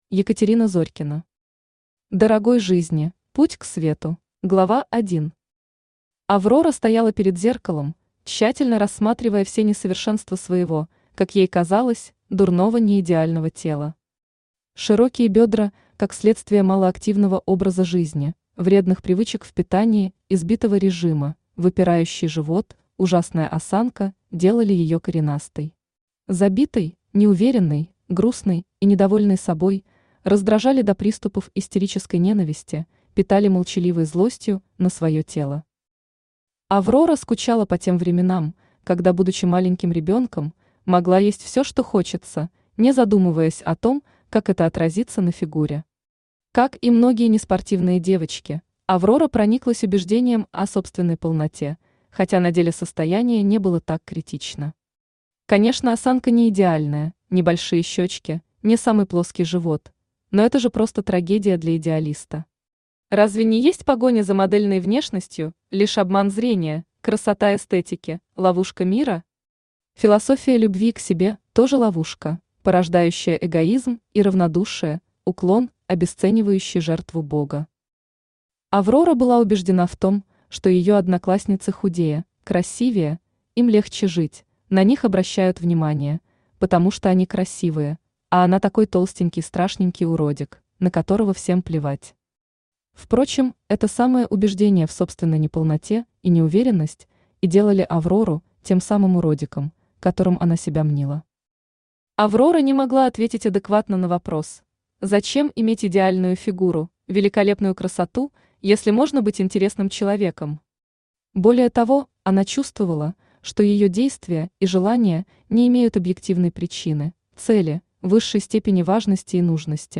Аудиокнига Дорогой жизни: Путь к свету | Библиотека аудиокниг
Aудиокнига Дорогой жизни: Путь к свету Автор Екатерина Валерьевна Зорькина Читает аудиокнигу Авточтец ЛитРес.